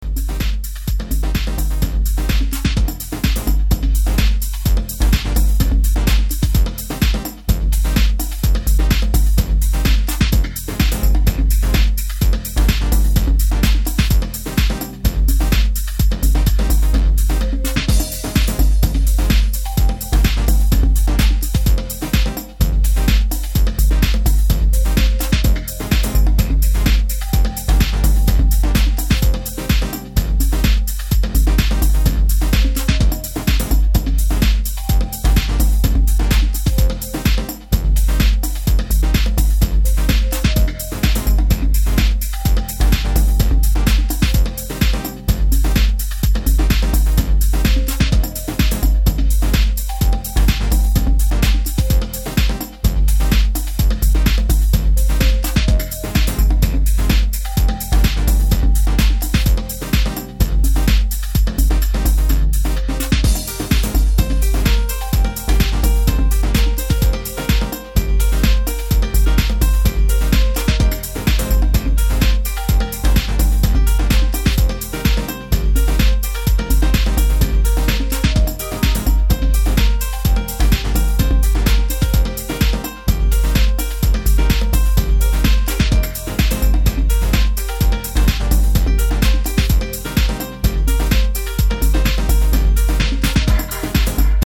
underground house classic